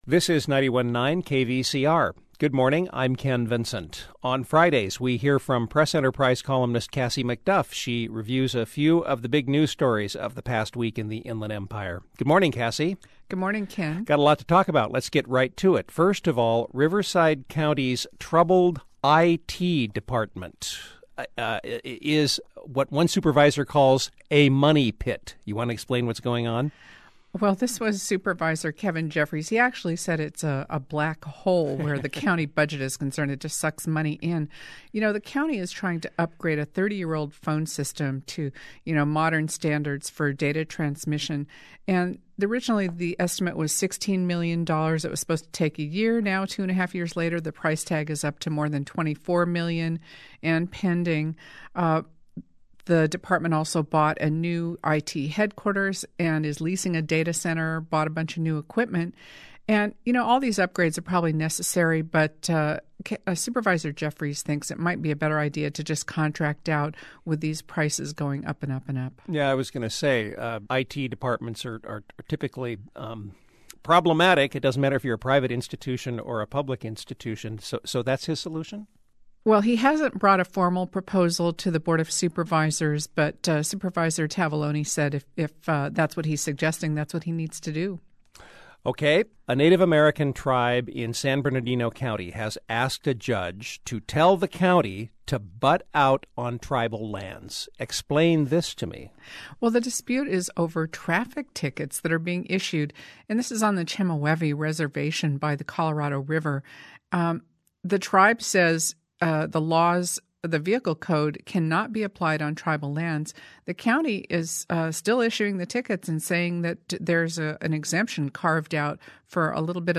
Local Civic Affairs